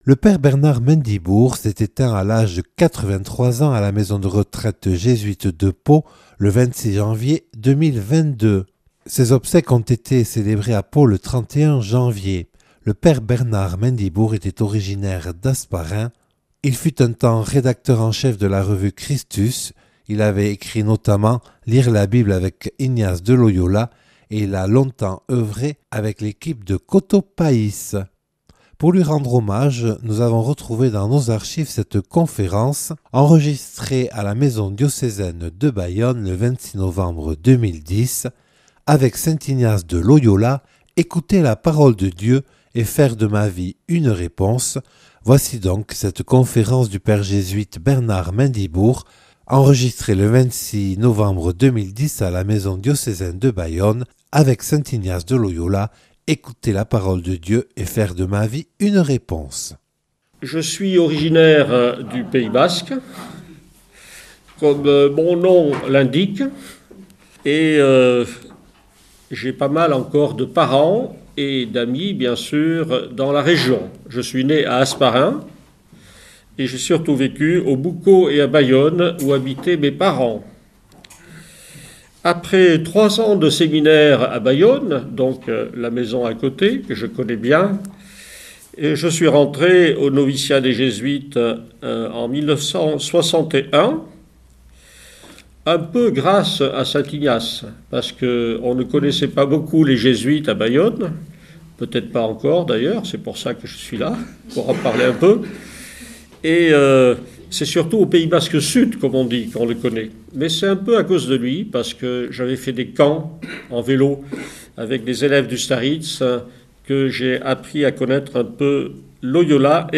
(Enregistré le 26/11/2010 à la maison diocésaine de Bayonne).